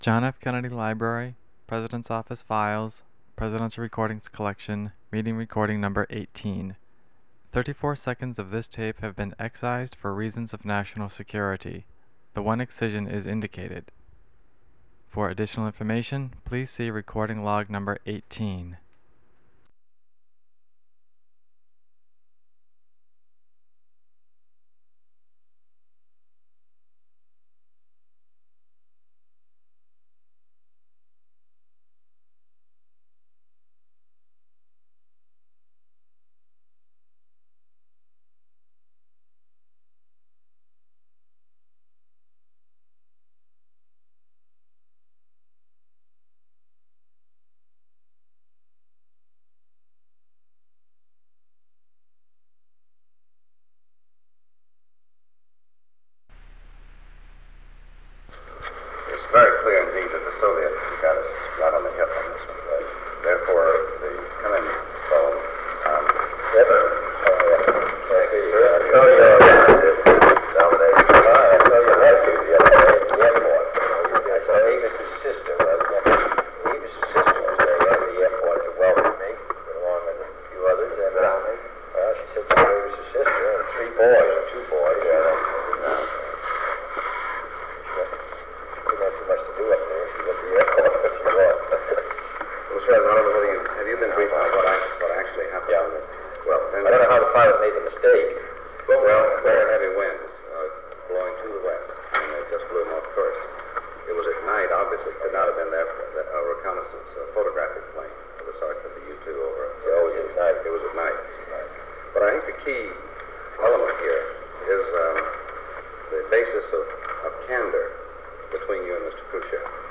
Secret White House Tapes | John F. Kennedy Presidency Meeting on U-2 Incident Rewind 10 seconds Play/Pause Fast-forward 10 seconds 0:00 Download audio Previous Meetings: Tape 121/A57.